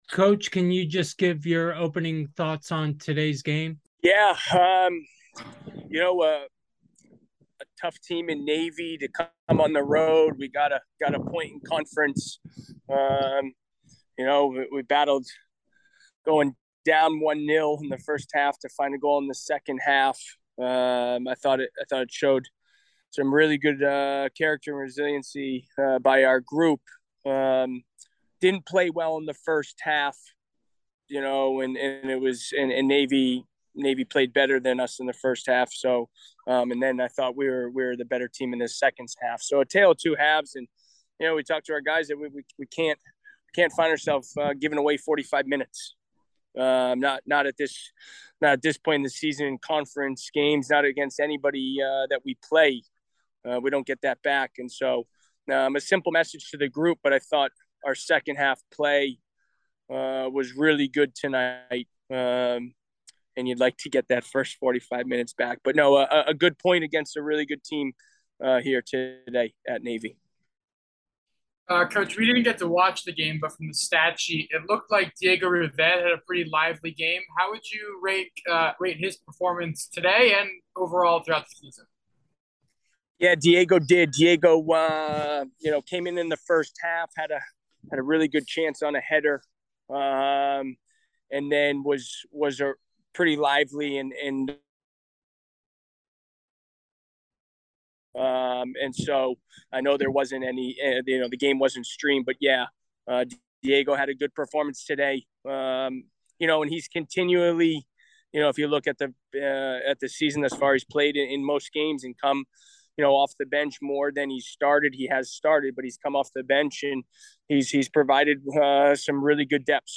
Navy Postgame Interview
Navy_postgame.mp3